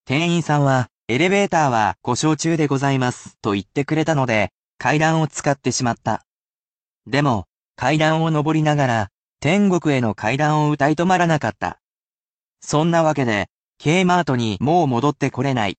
I will also read aloud the sentences for you, however those will be presented at natural speed.